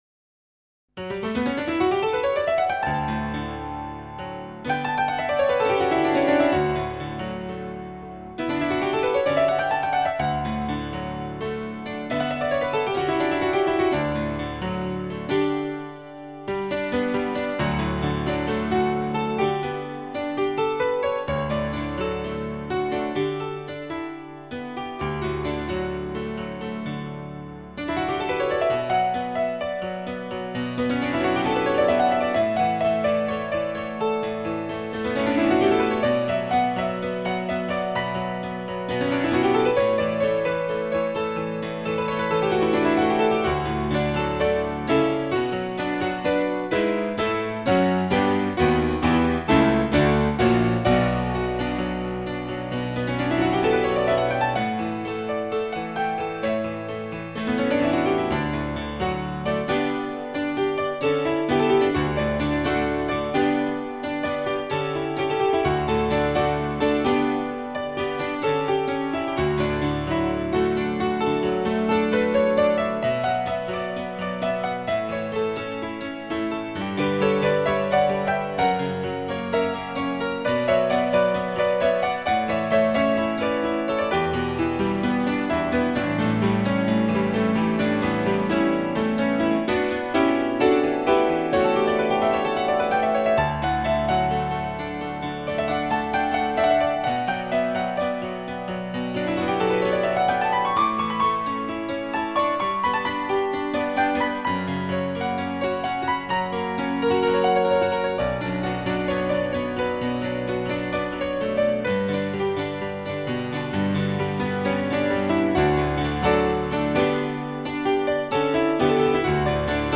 僅低音質壓縮 , 供此線上試聽
充滿寧靜 喜悅 生命力